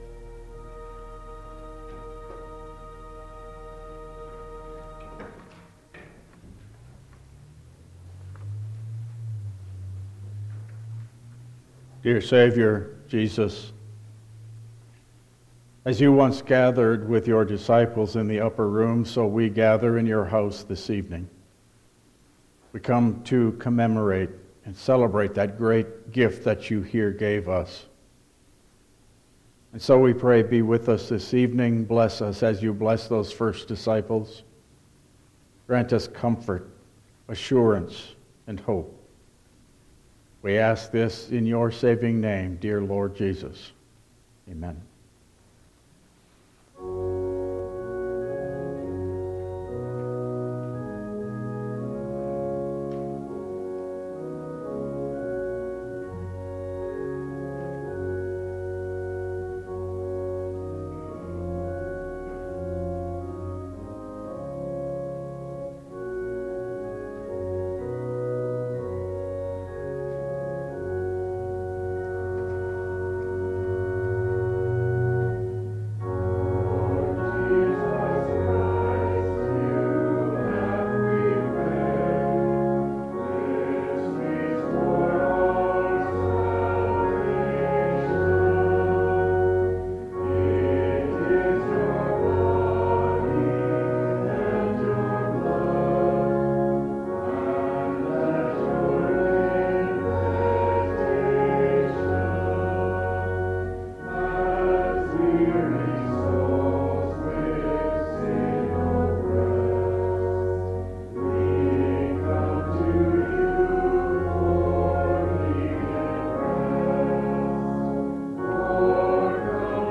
Service Type: Lenten Service